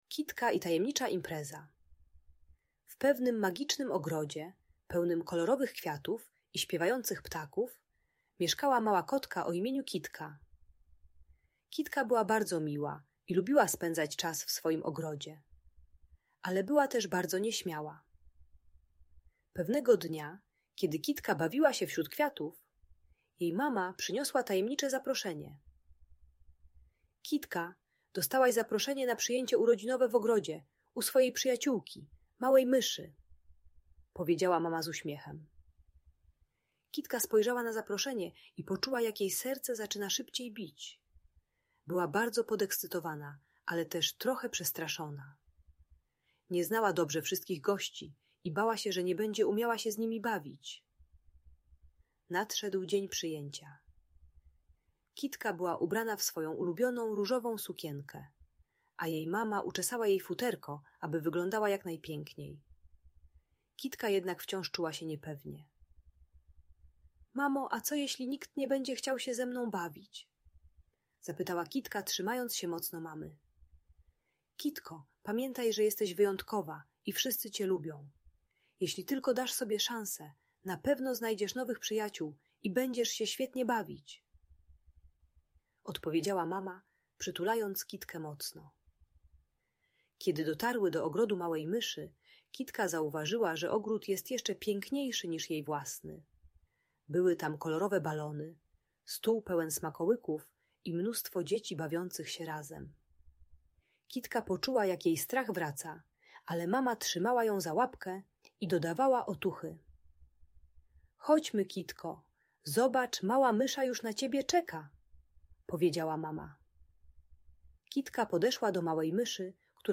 Ta audiobajka o lęku przed nową sytuacją uczy techniki małych kroków - znajdowania bezpiecznego miejsca i stopniowego dołączania do zabawy.